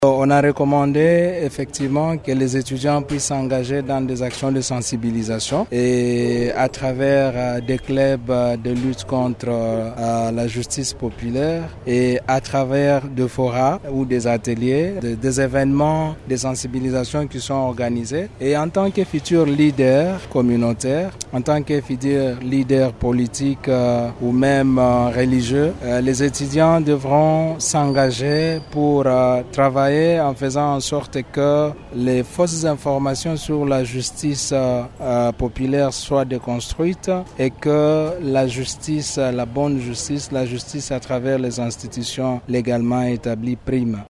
Deux thèmes à savoir la gestion des rumeurs et la justice populaire face au Droit positif congolais ont été abordés au cours d’une conférence débat organisée samedi 21 juin 2025 par Radio Maendeleo en faveur des étudiants de l’Institut Supérieur Pédagogique ISP Bukavu.